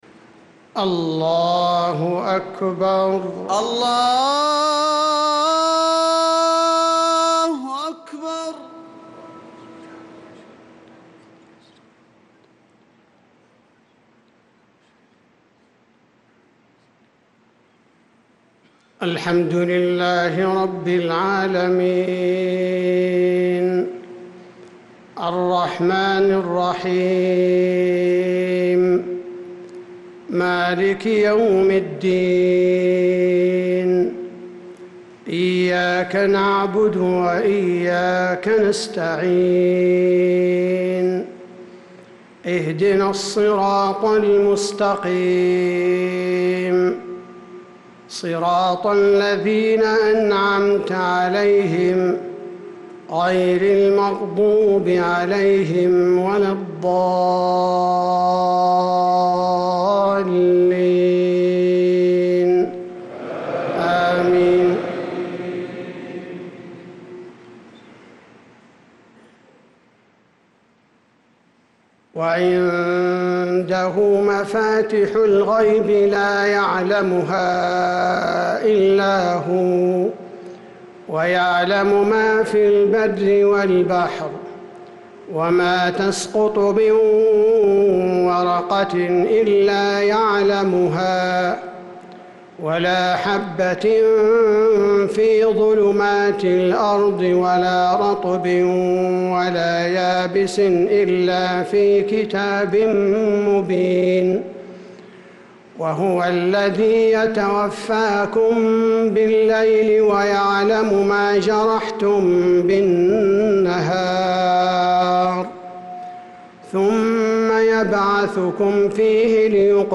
صلاة العشاء للقارئ عبدالباري الثبيتي 6 ذو الحجة 1445 هـ
تِلَاوَات الْحَرَمَيْن .